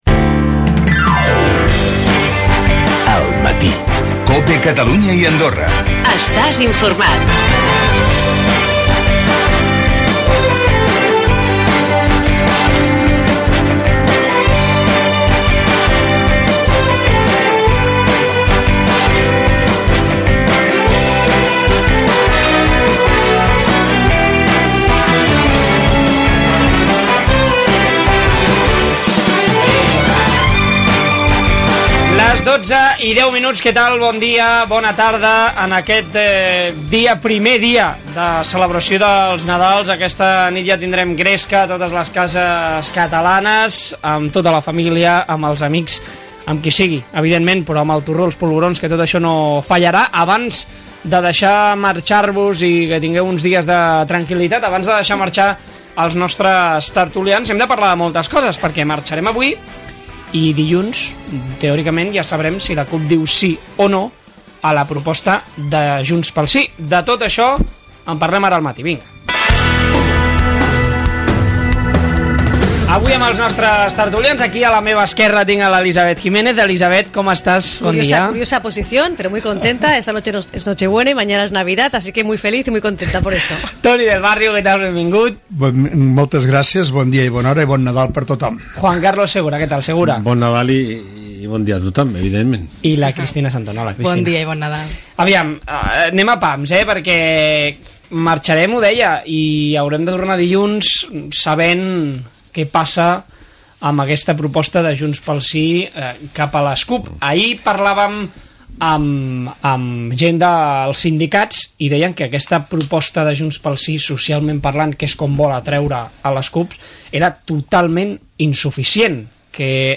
Indicatiu del programa, la tertúlia d'actualitat política